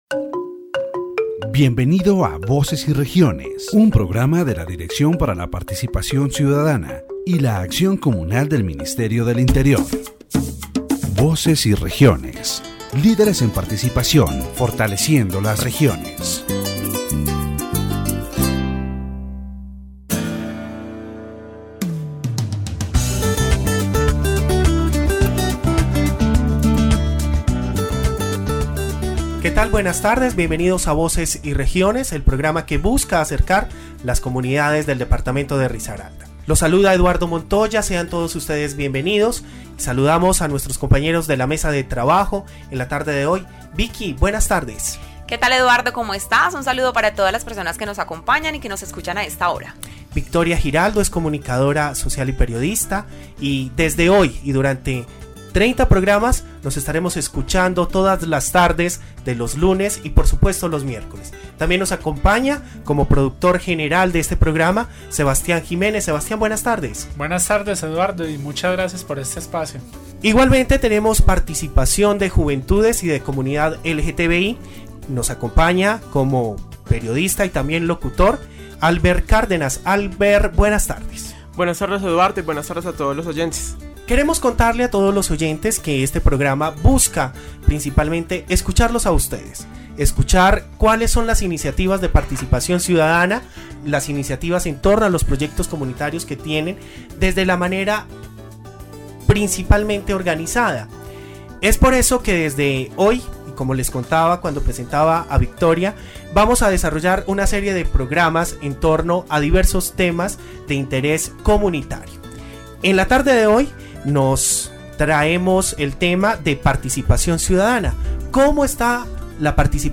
The governor of Risaralda, Carlos Alberto Botero López, highlights the participatory budget processes and citizen meetings in the department.